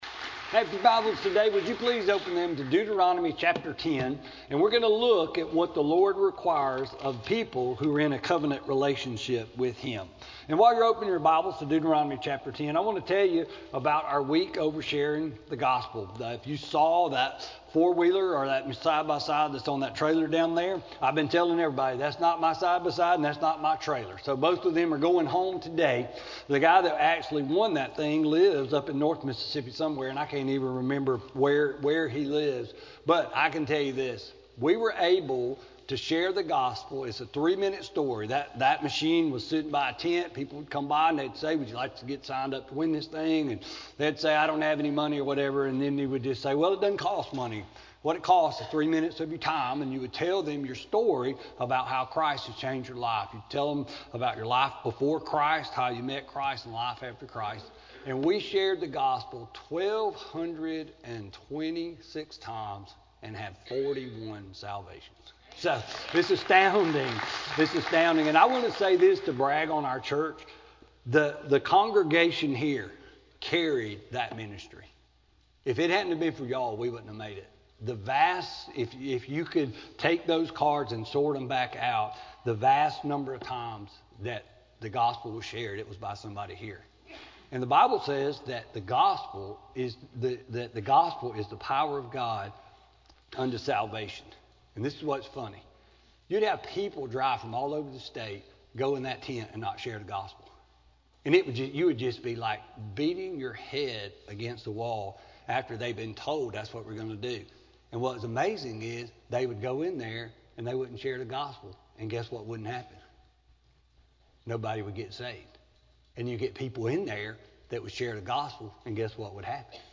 Sermon-2-10-19-CD.mp3